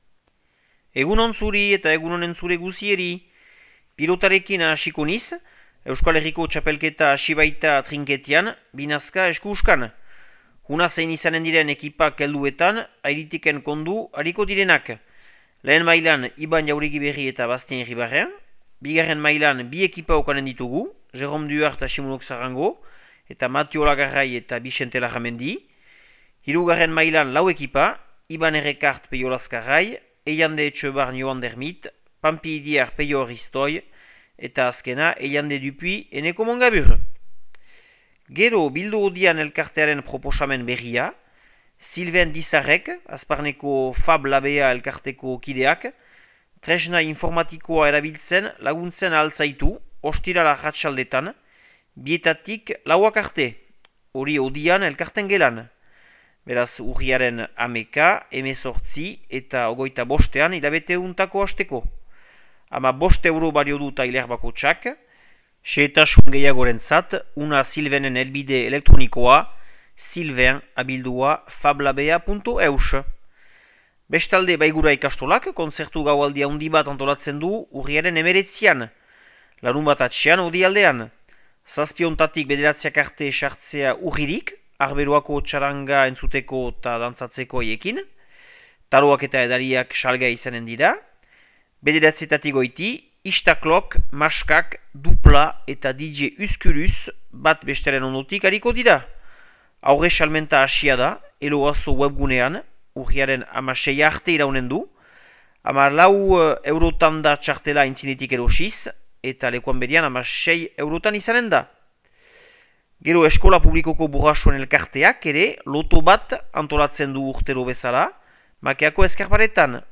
Urriaren 7ko Makea eta Lekorneko berriak